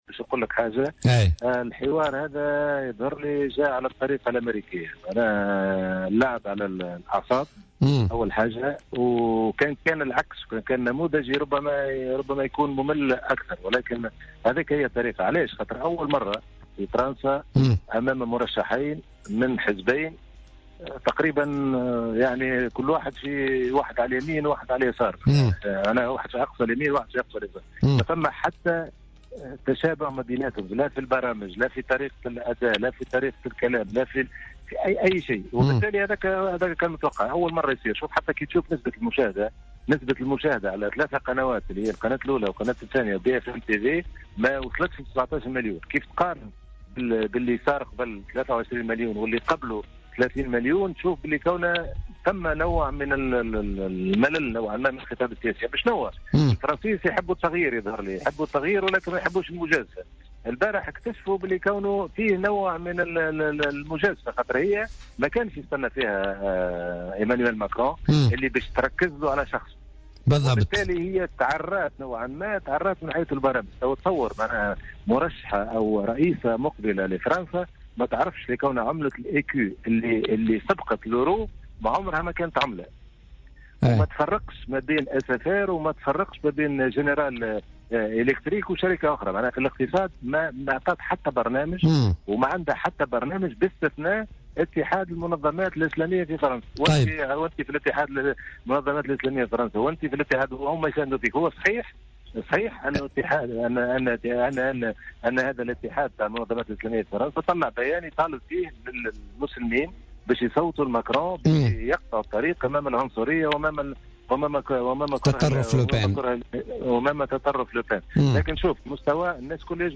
مداخلة له اليوم في برنامج "بوليتيكا"